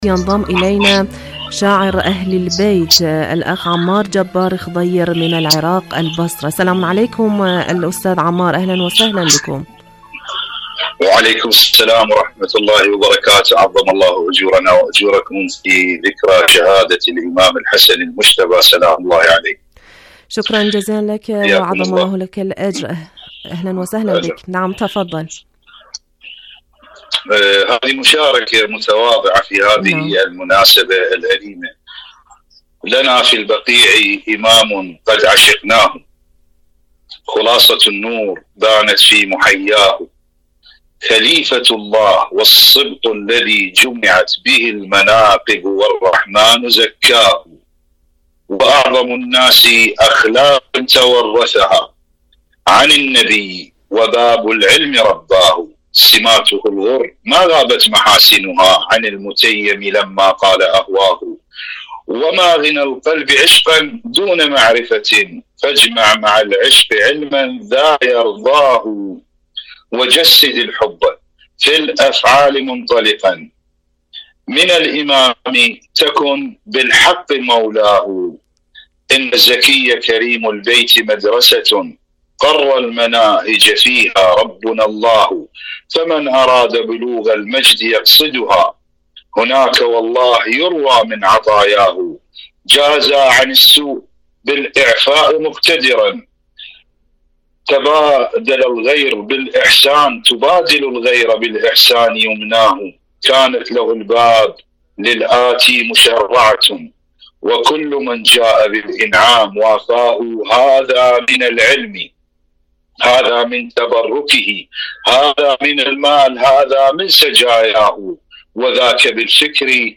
إذاعة طهران- شهادة الإمام الحسن (ع): مقابلة إذاعية